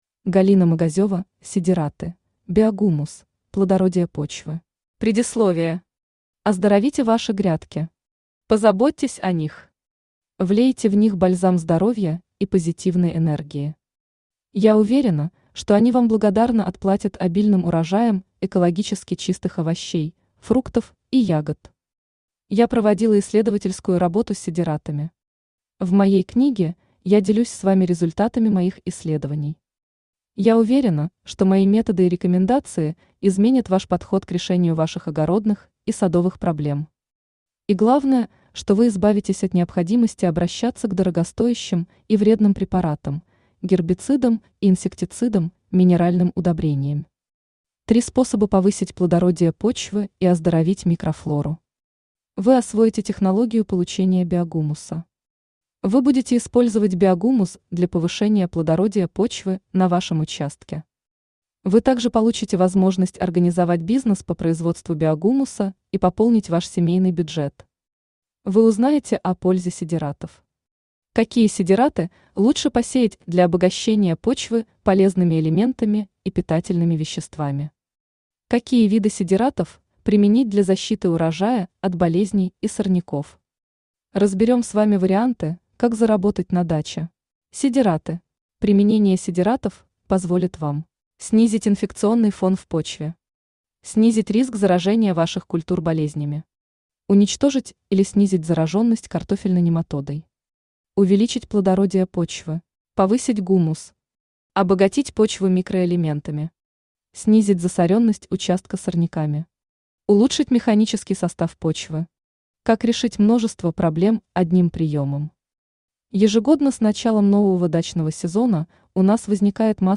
Аудиокнига Сидераты. Биогумус. Плодородие почвы | Библиотека аудиокниг
Плодородие почвы Автор Галина Магазева Читает аудиокнигу Авточтец ЛитРес.